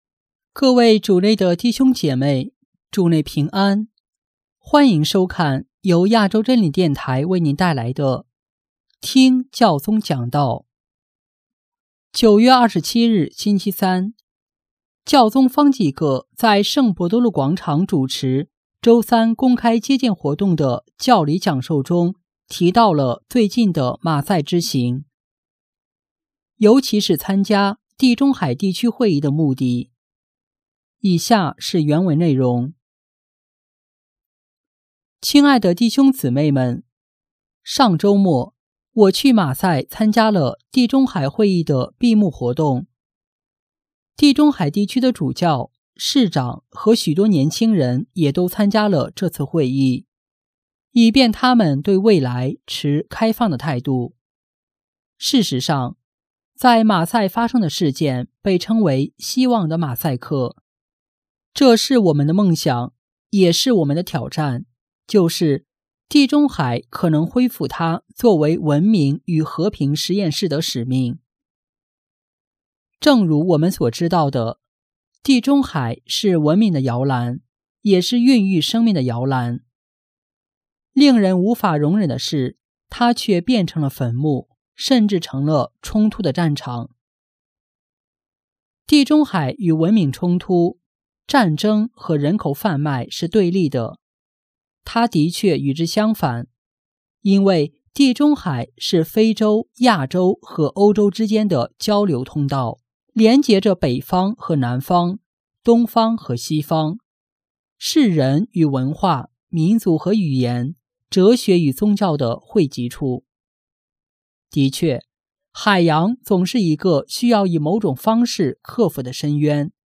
9月27日，星期三，教宗方济各在圣伯多禄广场主持周三公开接见活动的教理讲授中提到了最近的马赛之行，尤其是参加“地中海地区会议”的目的。